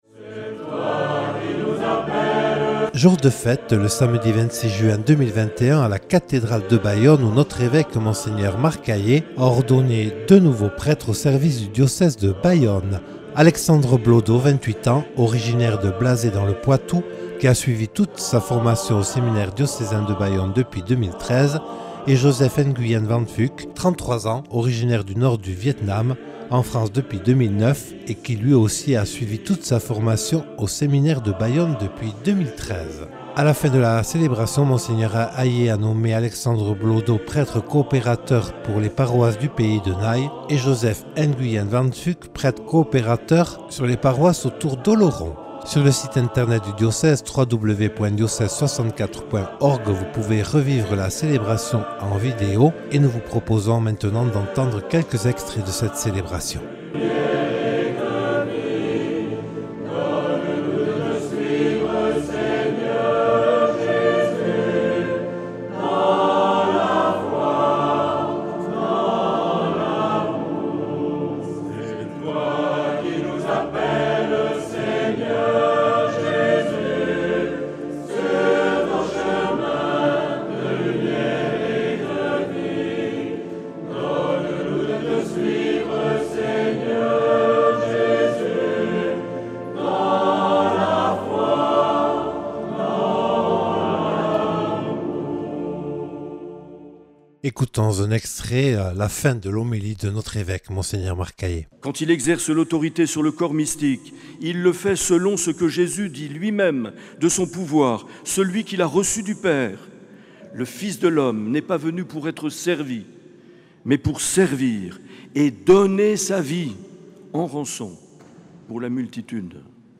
Voici quelques extraits de la célébration du 26 juin 2021 présidée par Mgr Aillet en la cathédrale de Bayonne.